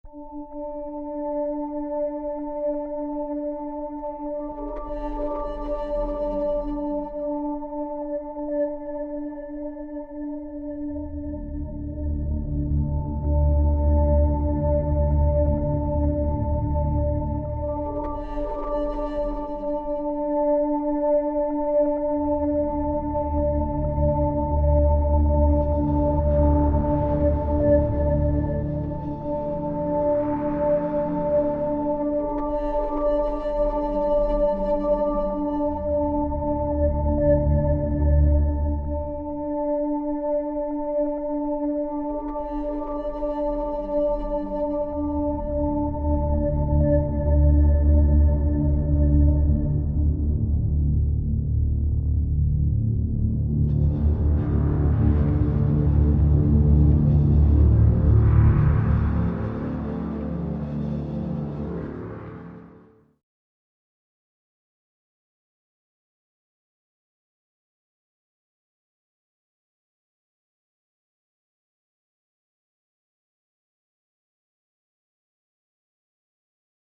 ohne Rhythmus